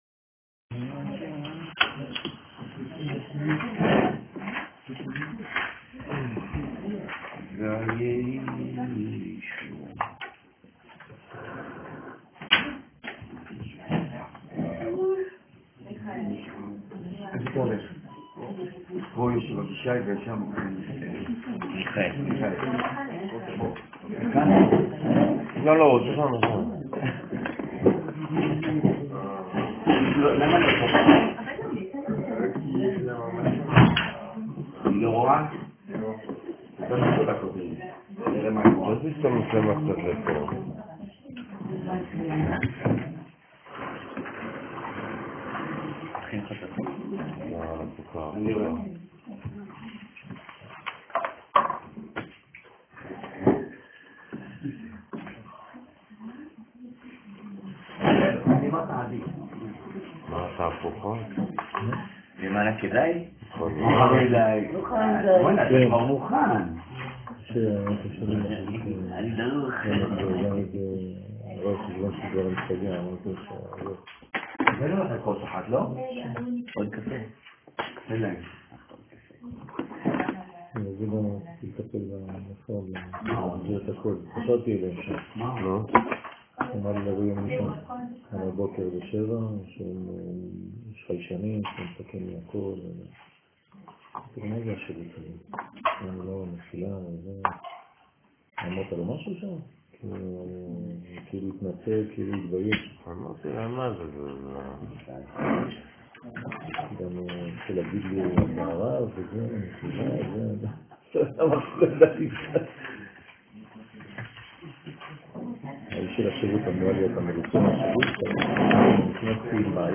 שיעור לילה : תיקוני זוהר על המלכות